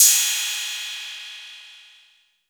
Crashes & Cymbals
TM-88 Crash #05.wav